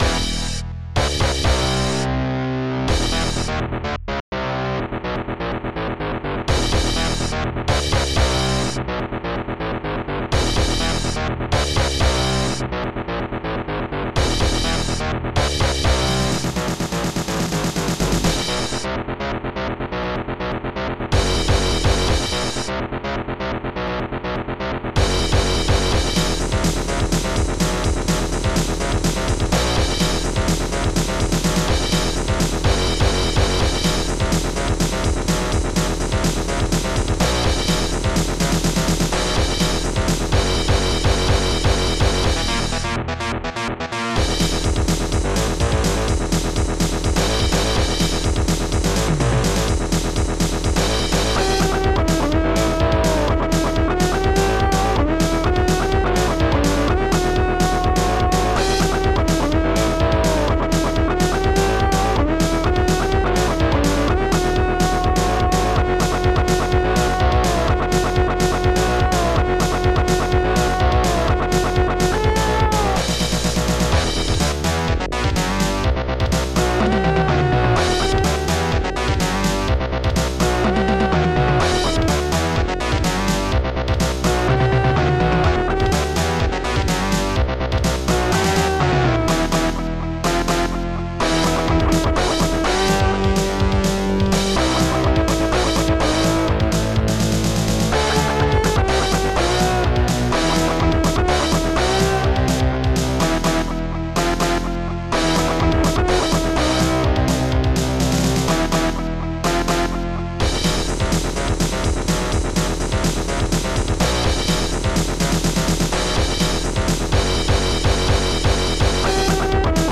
Protracker and family
ST-89:guitarbm2
ST-88:snare7
ST-87:bass94
ST-89:leadguit-led1
ST-89:distslide